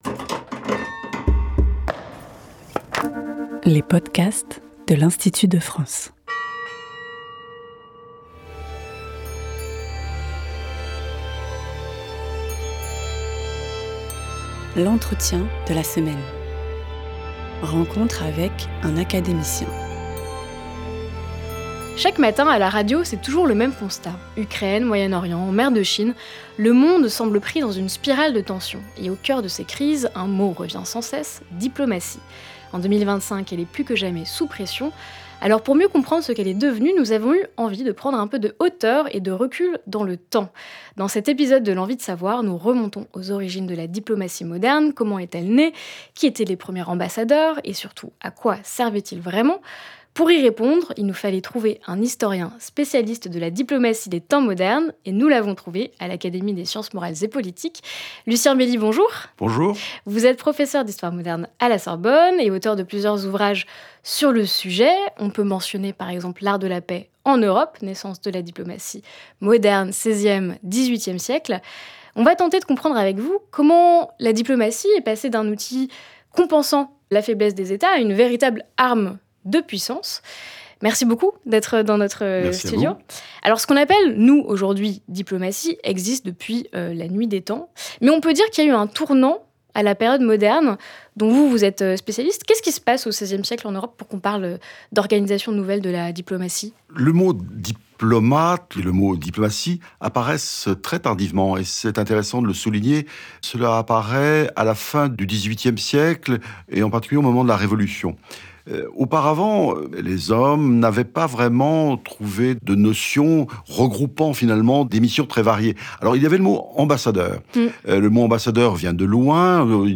Émission de culture générale. Chaque semaine, un nouvel invité (académicien, chercheur, etc) apporte des éclairages approfondis et nuancés sur un sujet tiré de sa spécialité.